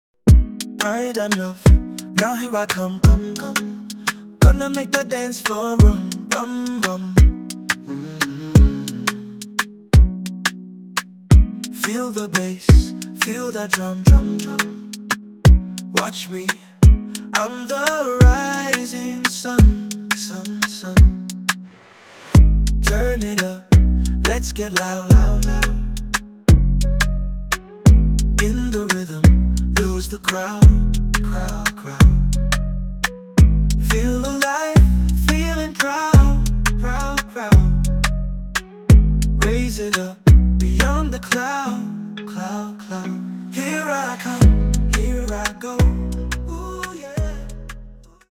Energetic
An incredible Reggae song, creative and inspiring.